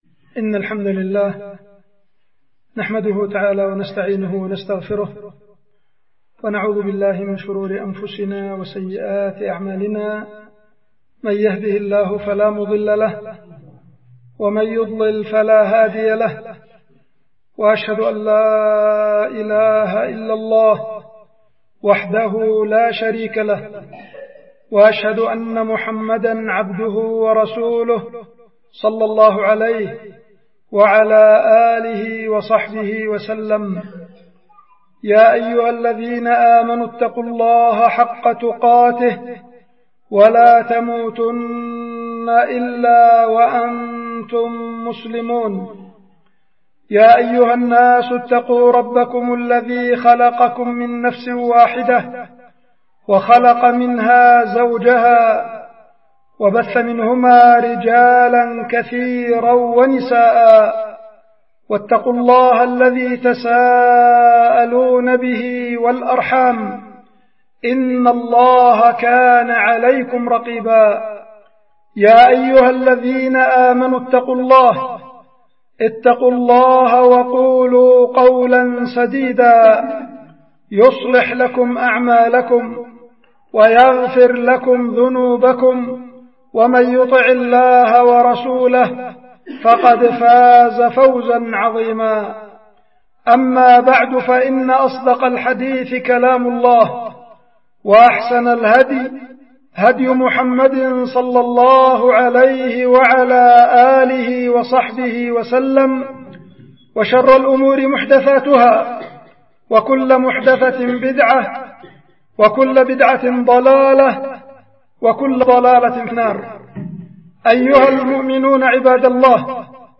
خطبة
القيت في مسجد العدنة بالعزلة- مديرية بعدان- إب-اليمن